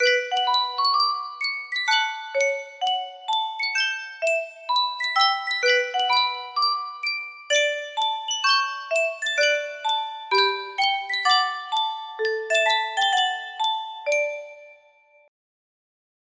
Sankyo Music Box - O Holy Night GHK music box melody
Full range 60